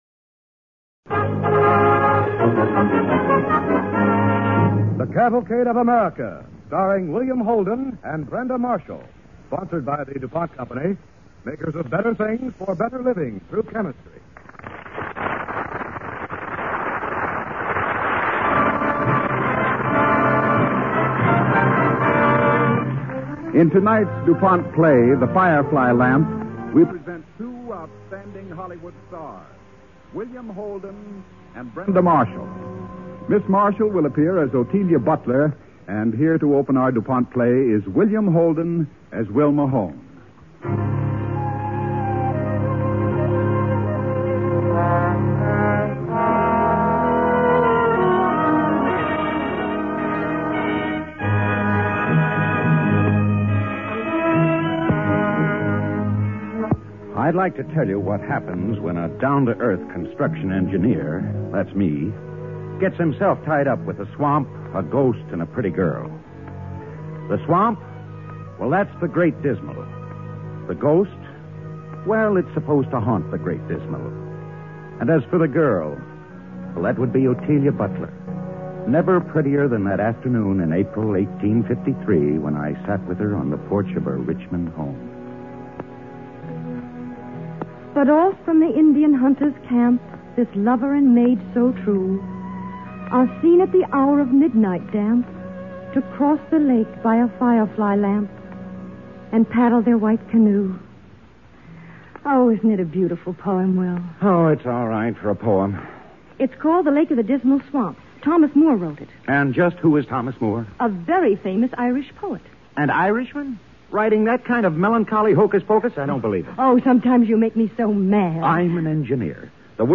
starring William Holden and Brenda Marshal-Holden
Cavalcade of America Radio Program